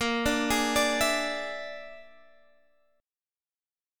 Bb7b5 chord